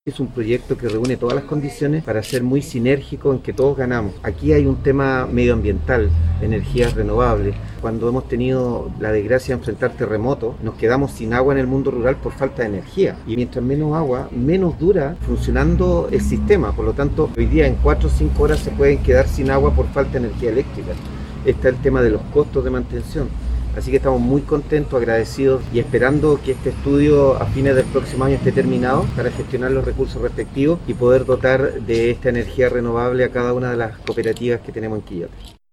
La presentación de la iniciativa se efectuó en la sede del Comité de Agua Potable Rural de La Palma, en Quillota y fue encabezada por el presidente de la Asociación Regional de Municipalidades y alcalde de Limache, Daniel Morales Espíndola.